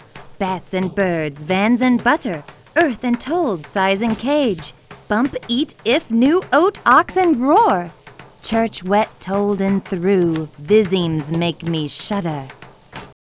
Recovered signal (RLS)
• NLMS appears to be better in first case while RLS is better in second case.